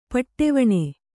♪ paṭṭevaṇe